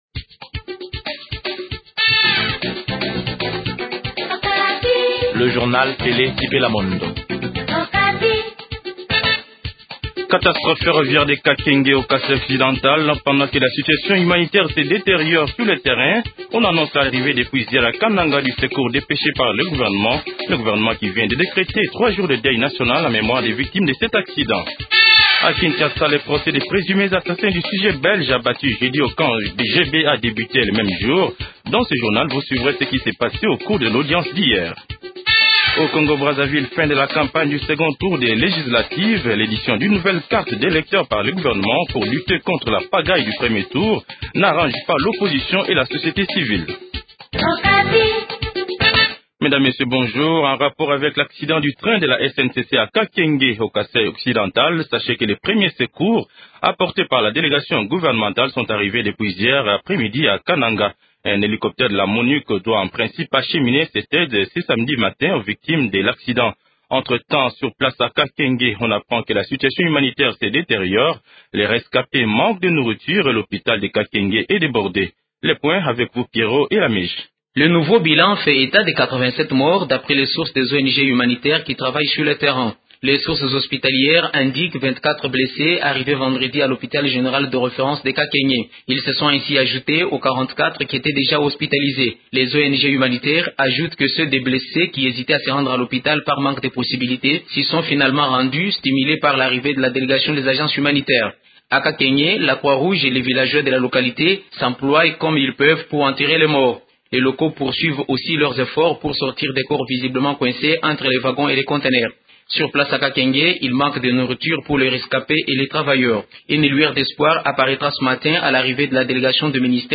Journal Français Matin 08h00